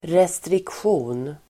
Ladda ner uttalet
restriktion substantiv, restriction Uttal: [restriksj'o:n]